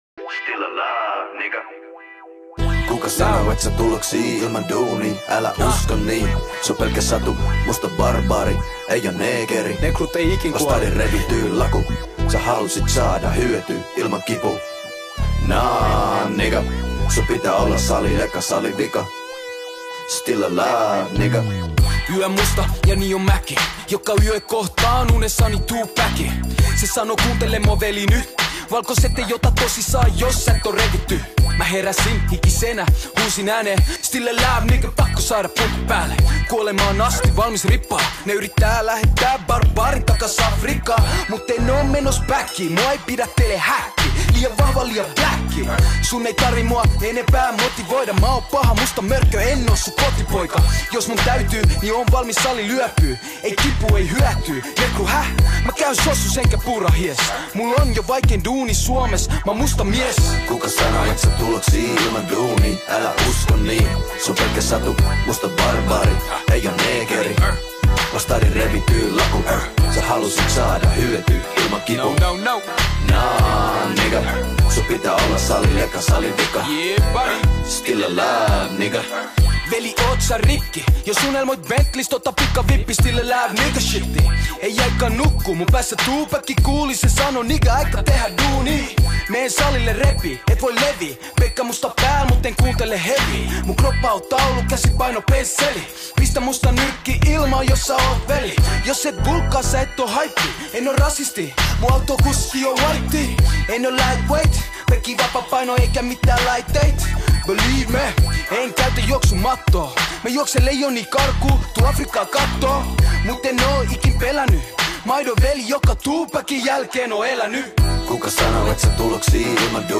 rap-artisti